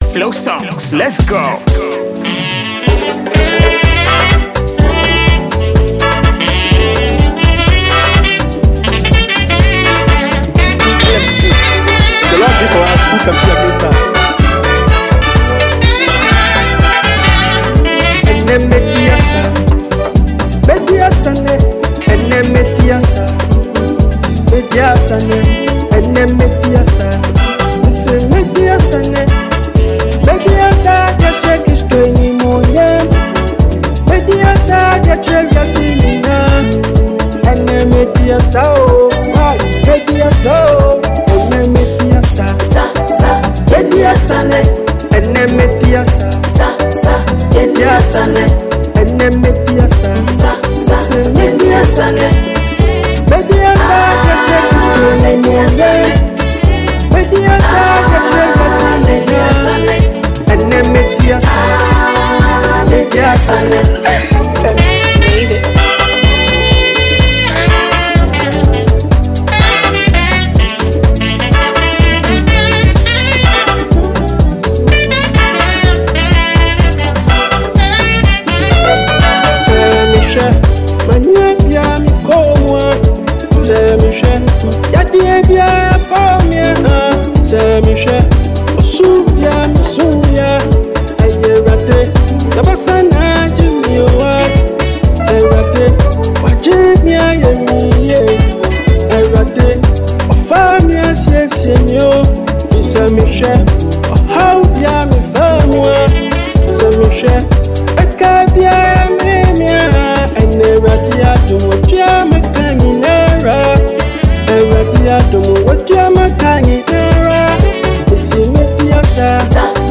It is a catchy and lively melody.
Afrobeat